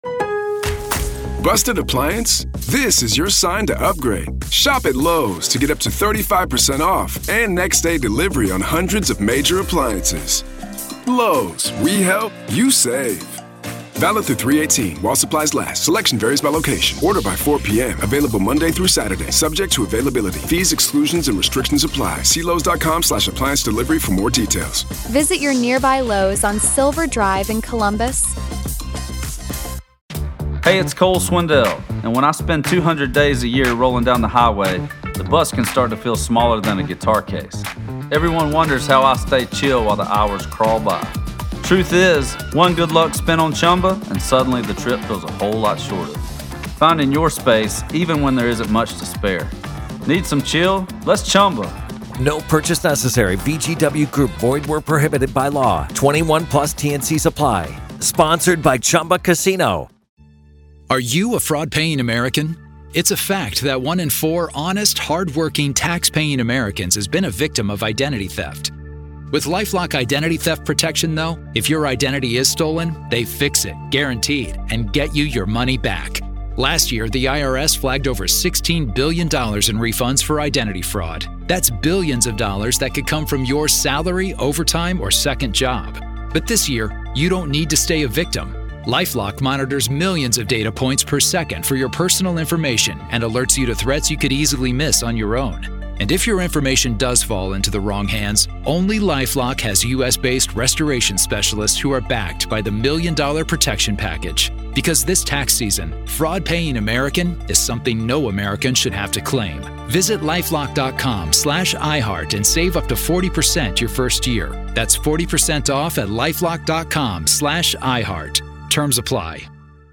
This is gavel-to-gavel coverage of one of the most closely watched trials in Utah history.
Hidden Killers brings you complete trial coverage with expert analysis—no sensationalism, just the facts as they unfold.